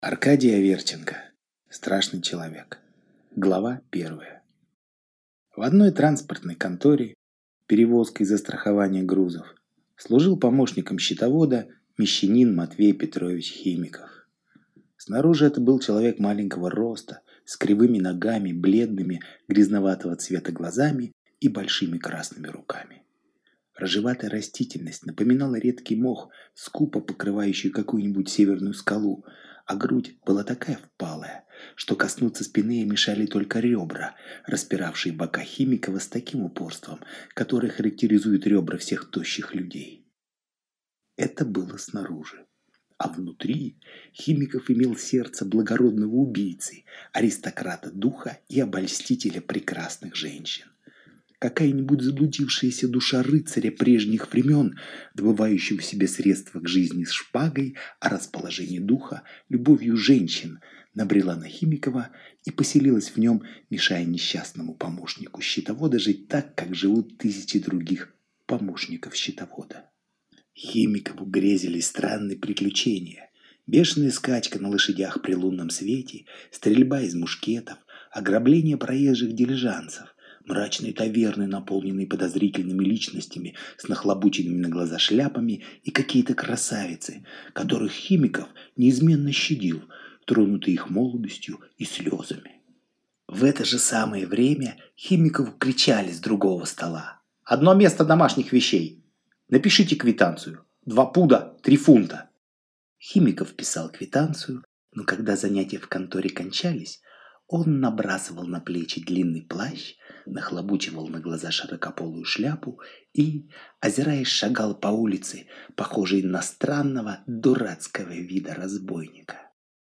Aудиокнига Страшный человек Автор Аркадий Аверченко Читает аудиокнигу Алгебра Слова.